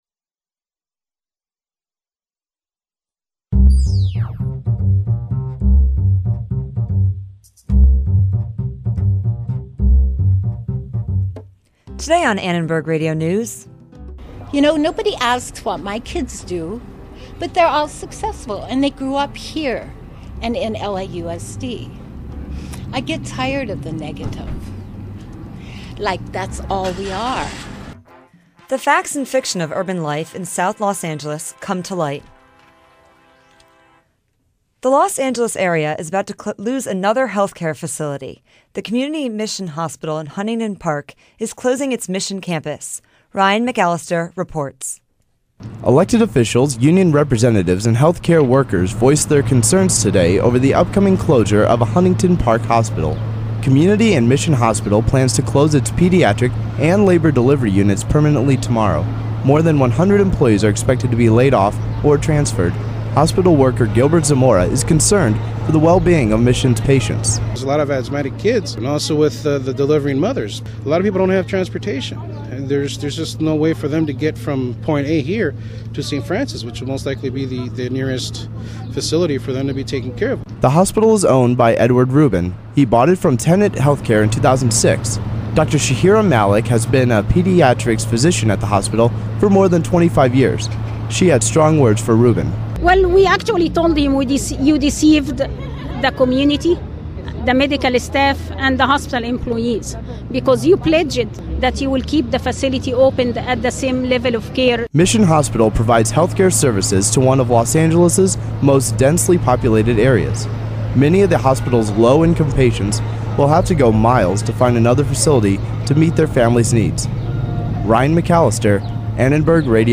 As the citywide homicide rate of gang-related shootings in South Los Angeles rate keeps climbing, we talk to gang experts and a spokesperson for one of the family's victims. Also, a recently released memoir describing gang life in South Central Los Angeles has stirred up controversy after it was discovered as a work of fiction.
And we talk to award-winning novelist and distinguished English professor T.C. Boyle about the perceptions of sensationalized stories of struggle and the issue of plagiarism in the publishing industry.